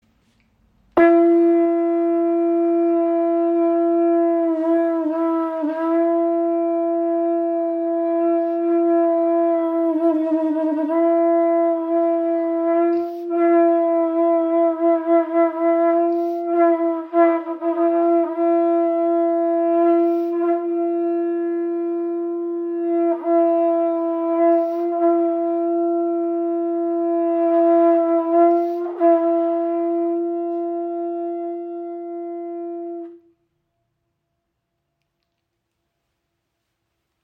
• Icon Signalhorn - TUWA in verschiedenen Grössen
• Icon Kräftiger Klang der weit herum hörbar ist
Sein kraftvoller, durchdringender Klang verbindet Dich mit den Ahnen, der Wildnis und der alten Tradition.
Büffelhorn auch "TUWA" genannt